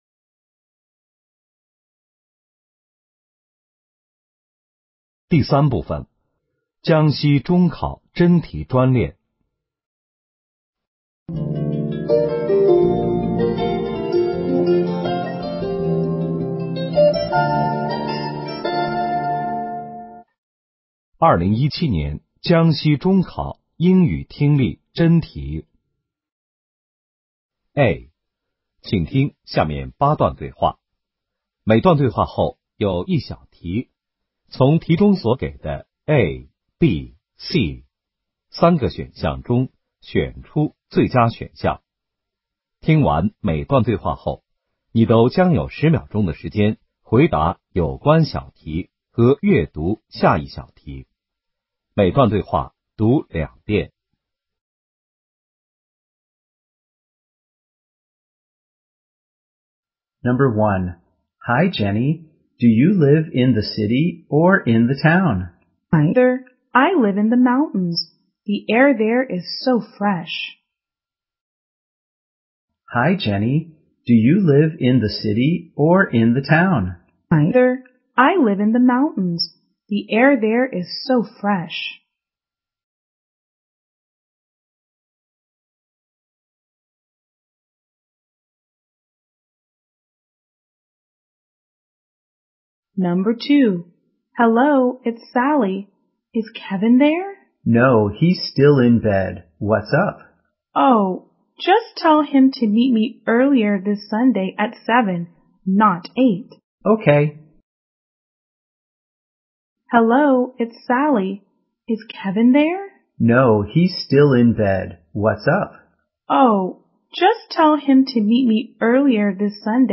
2017年江西中考英语听力真题：